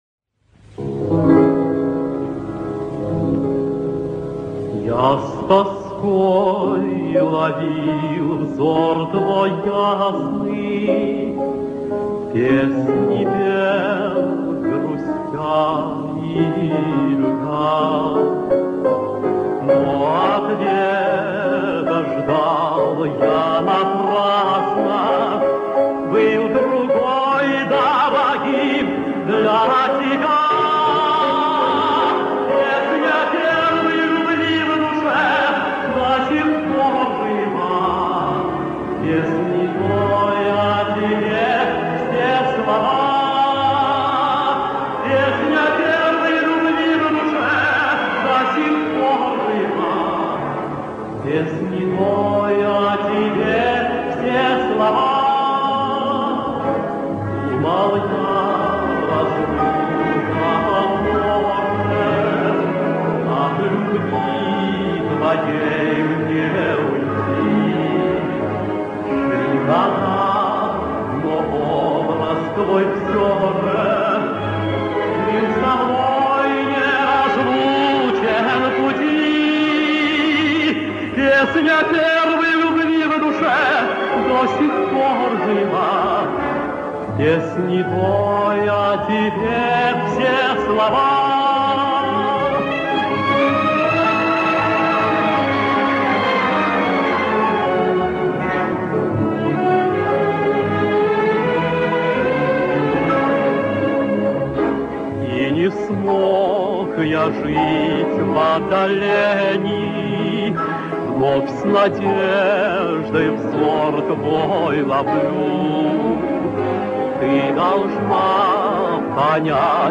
Звуковые дорожки песен из старых фильмов.